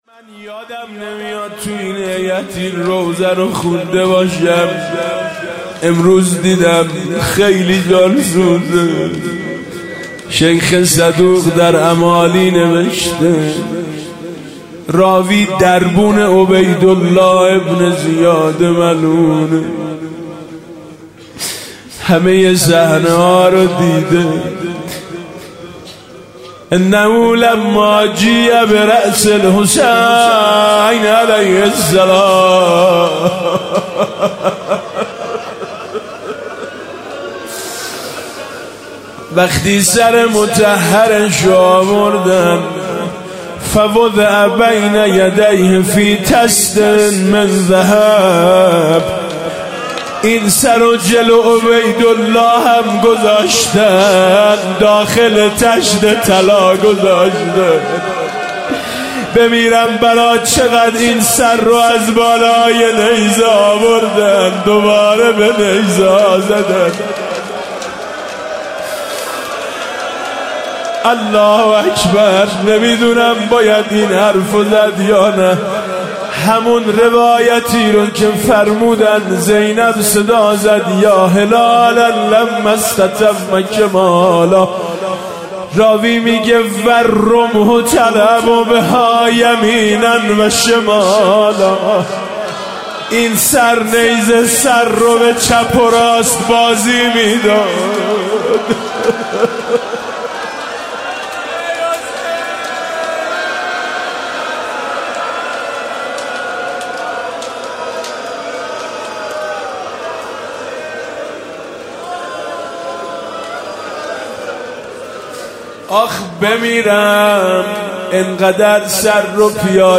هفتگی میثم مطیعی مداحی مقتل